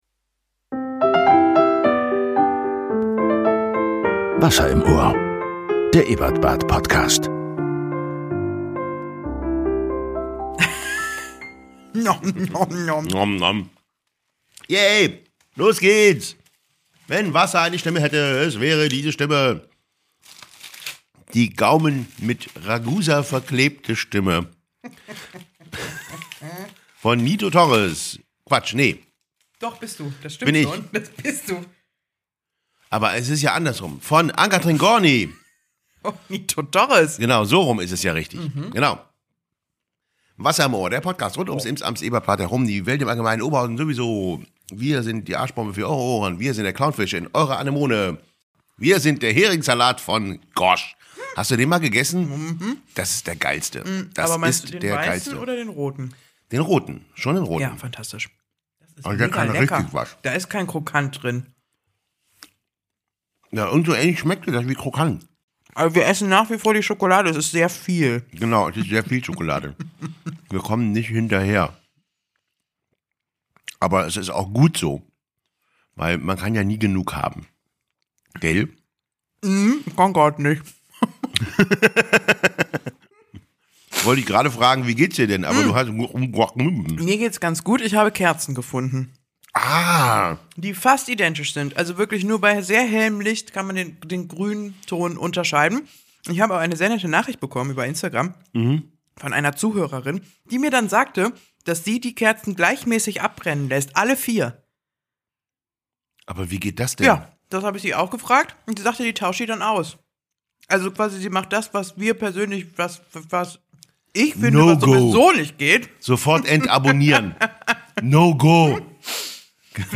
Im Interview: Thomas Krey, Bezirksbürgermeister Osterfeld a.D. und Hühnerzüchter. Absichtserklärungen, Münder voller Schokolade, ein Skandal, die Kerzendebatte nimmt ihren Lauf.